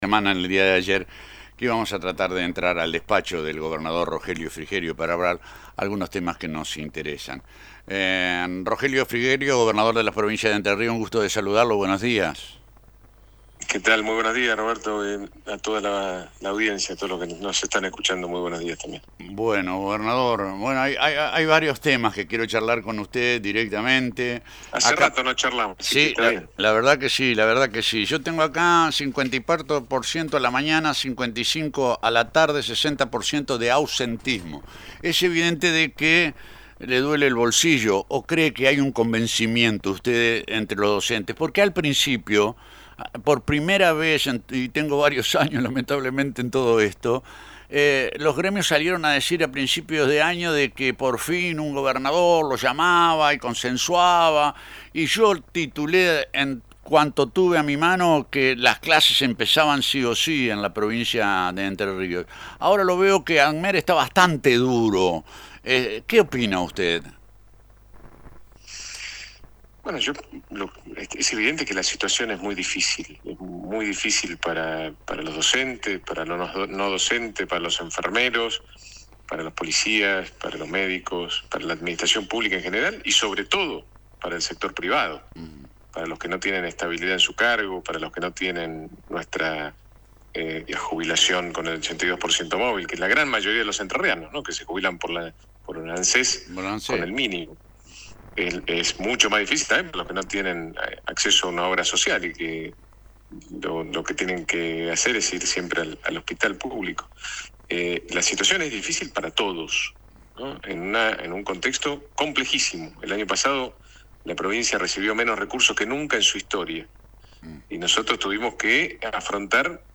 El gobernador de Entre Ríos habló en Radio Victoria sobre la crisis financiera de la provincia, los desafíos en infraestructura y el esfuerzo por mejorar los salarios.